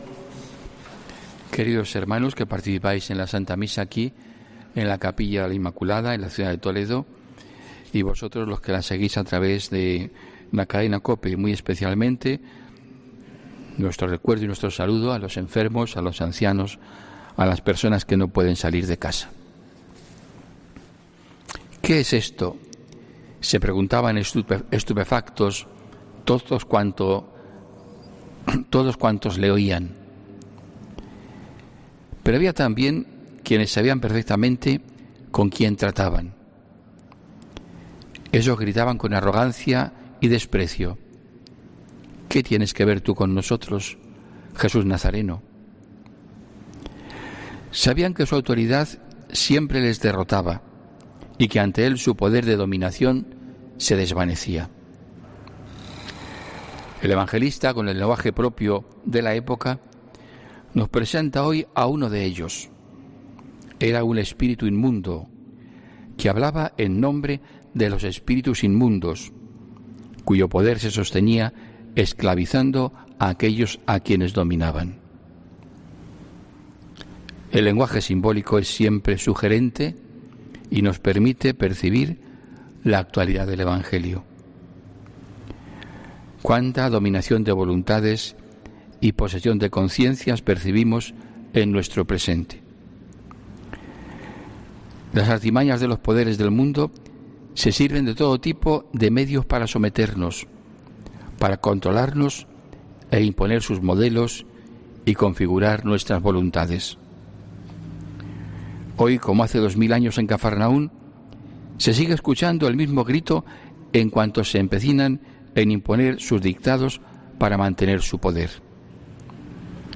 HOMILÍA 31 ENERO 2021